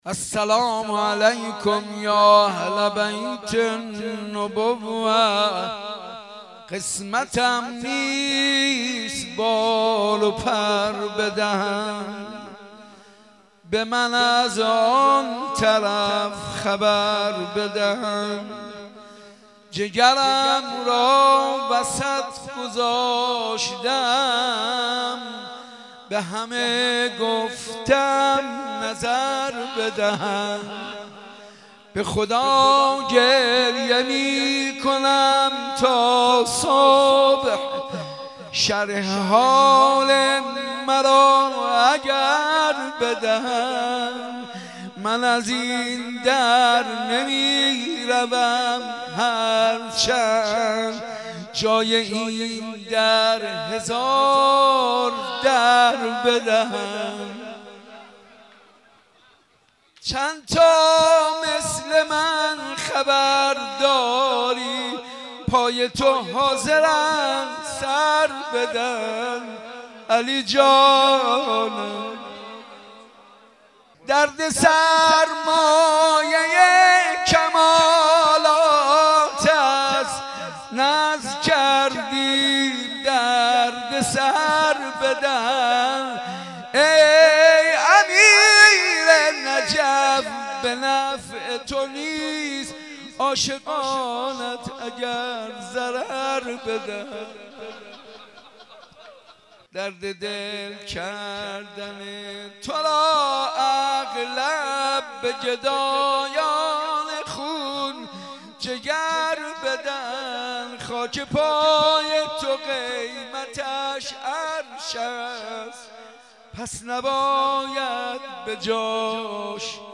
ولادت حضرت علی 13رجب مولودی